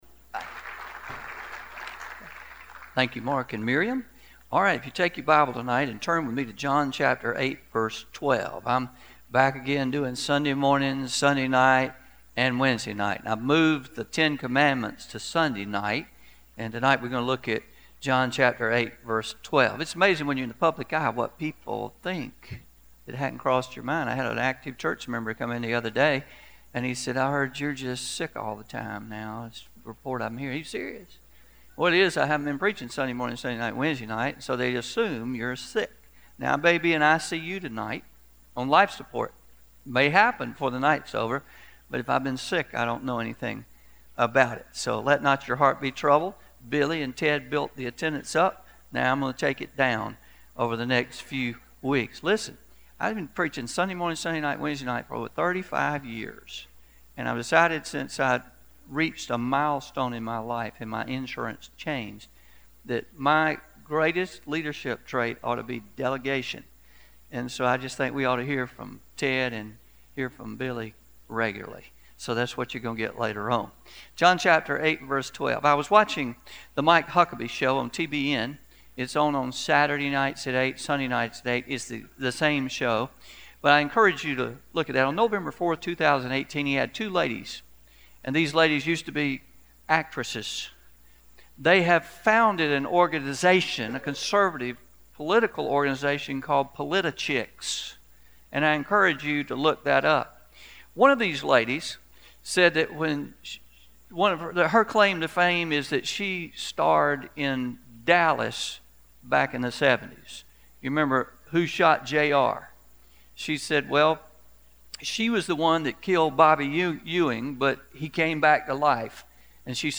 03-06-19pm Sermon – I am the Light of the World